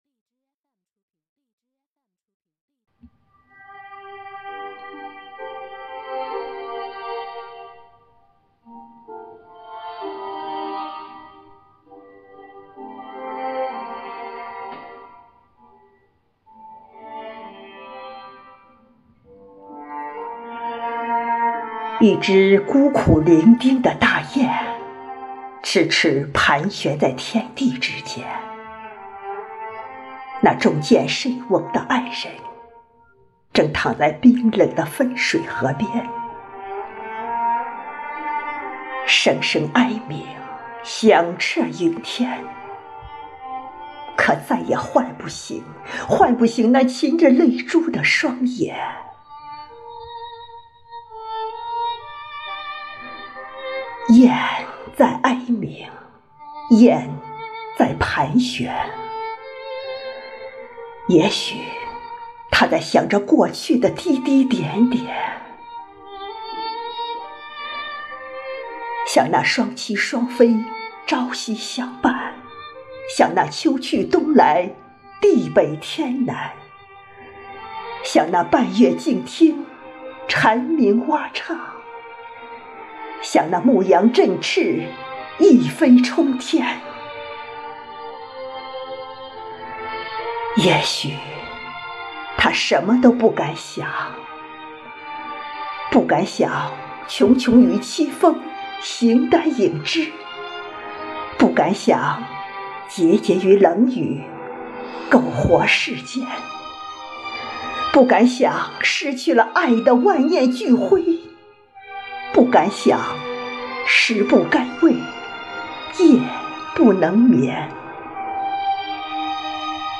业余朗诵爱好者